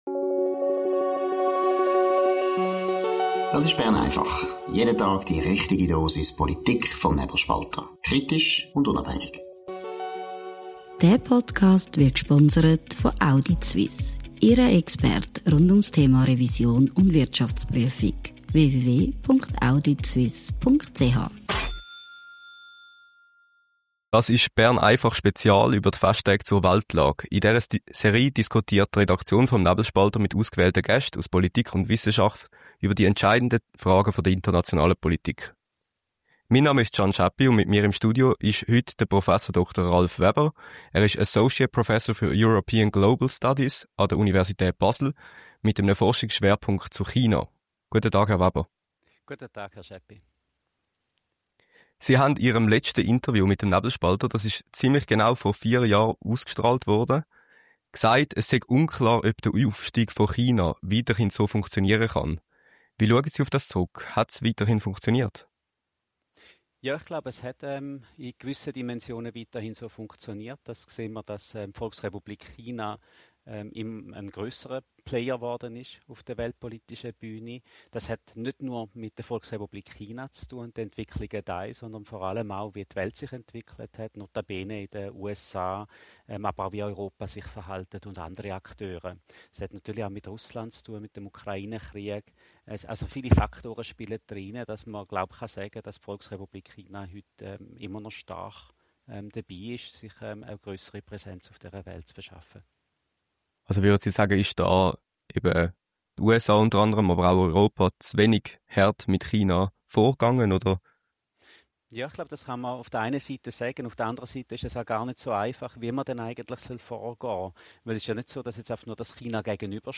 Bern einfach Spezial zur Weltlage: Die Redaktion des Nebelspalters spricht mit ausgewählten Gästen aus Politik, Journalismus und Wissenschaft über die entscheidende Frage der internationalen Politik.